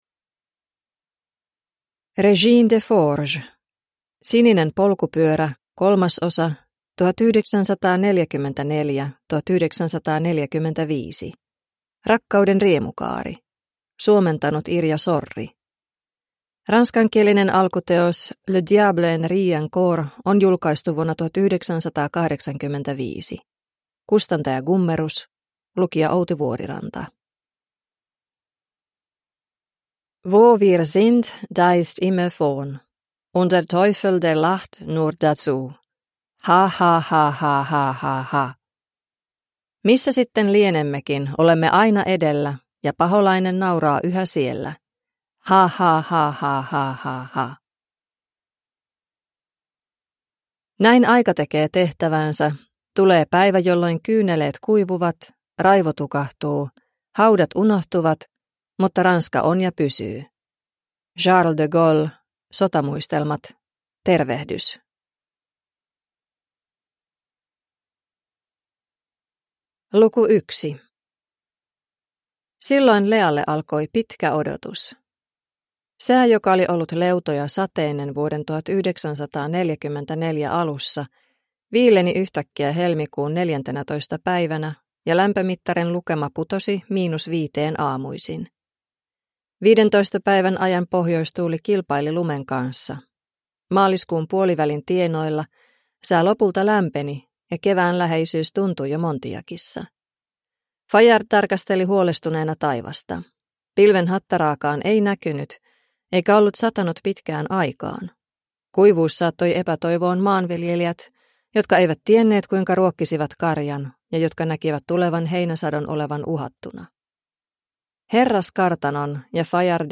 Rakkauden riemukaari – Ljudbok – Laddas ner
Romanttisen jännityskirjallisuuden klassikko vihdoinkin äänikirjana!